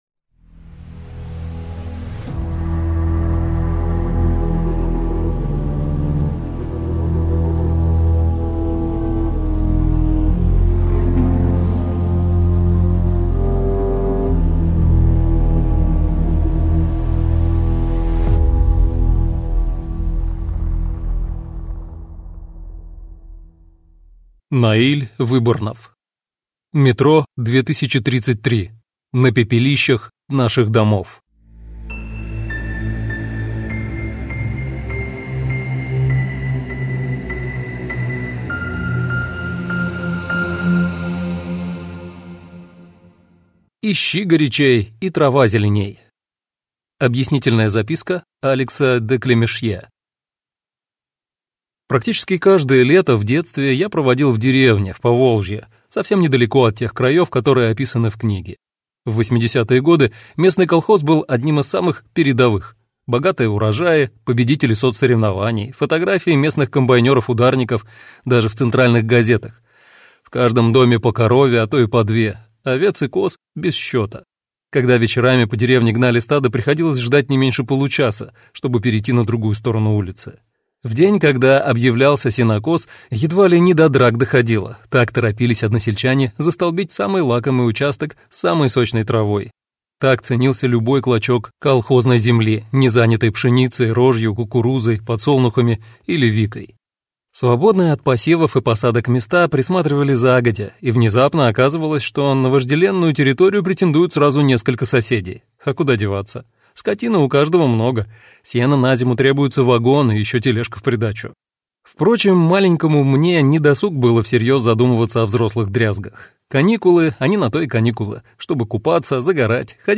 Аудиокнига Метро 2033: На пепелищах наших домов | Библиотека аудиокниг